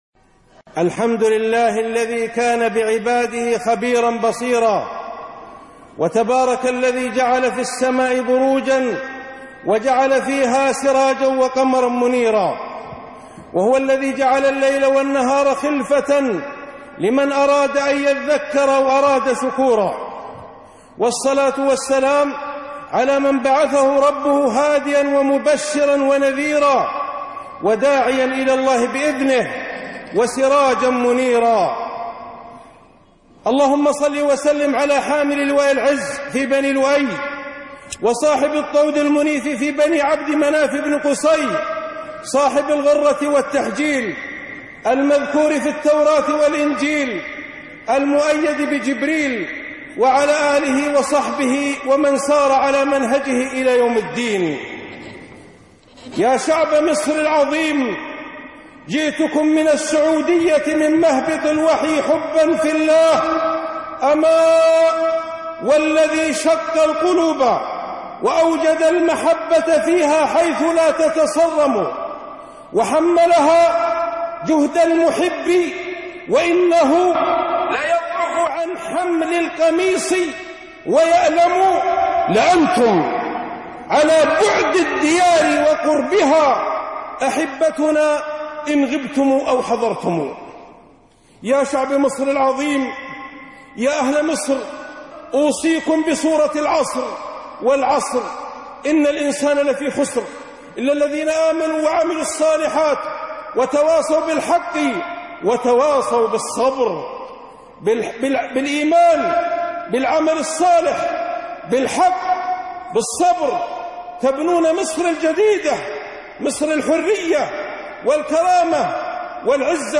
خطبه د/عائض-أيها الشعب المصري العظيم-وصية للشعب المصري - الدكتور عائض القرنى